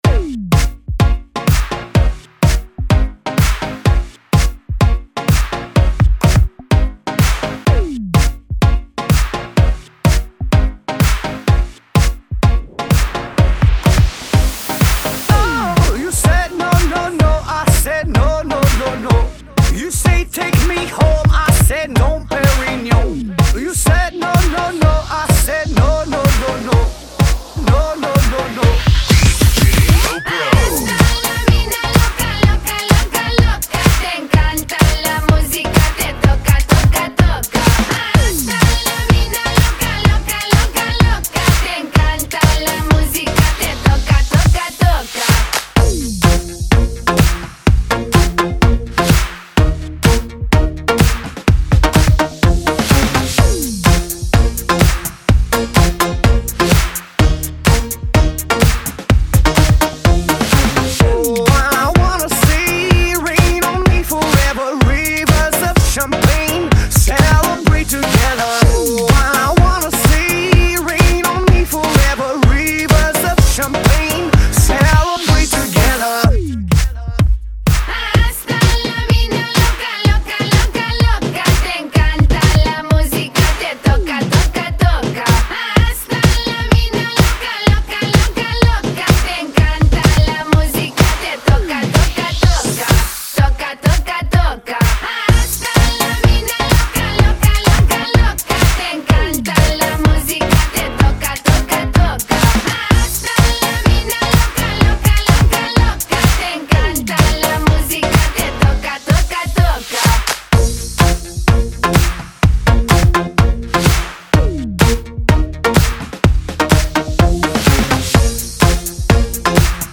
[ 126 Bpm ]